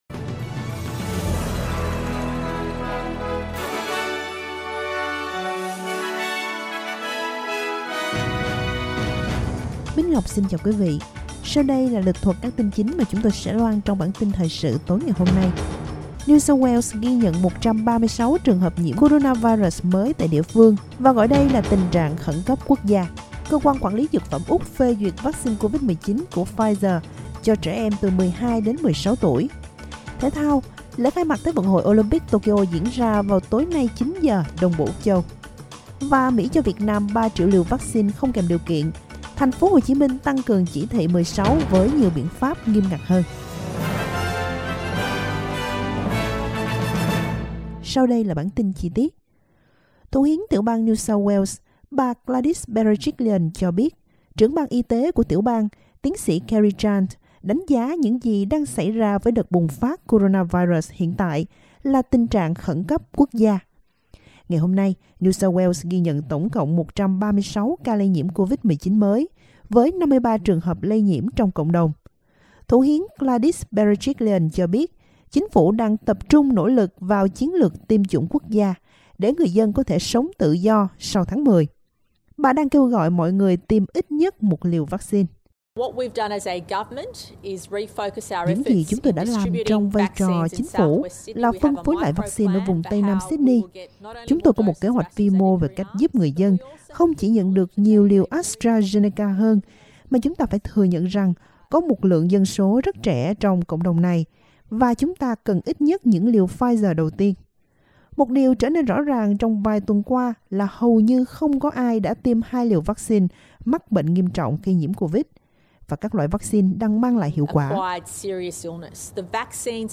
Vietnamese news bulletin Source: Getty
vietnamese_news1_237.mp3